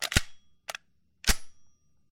musket_cock.ogg